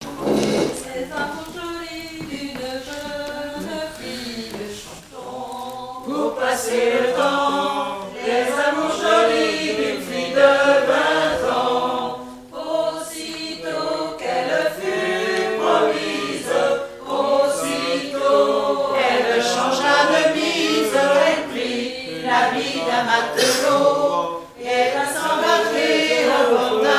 Genre strophique
7e festival du chant traditionnel : Collectif-veillée
Pièce musicale inédite